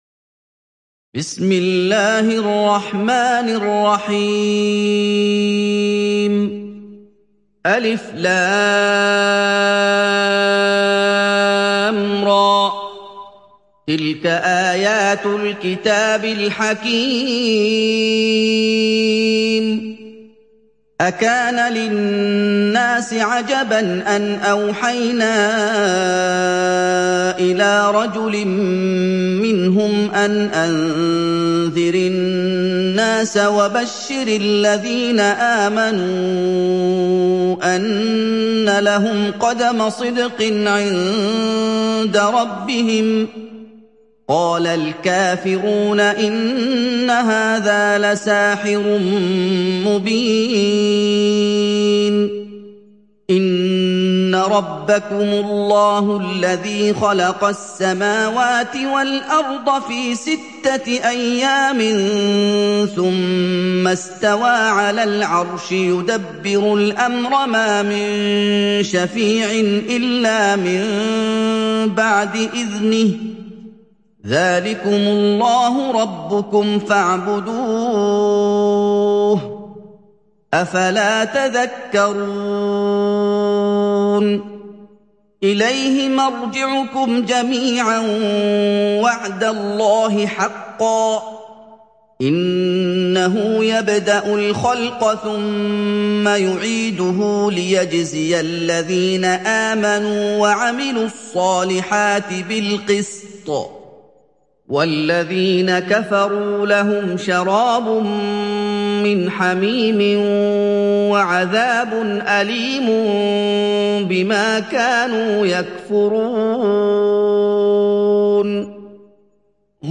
Yunus Suresi mp3 İndir Muhammad Ayoub (Riwayat Hafs)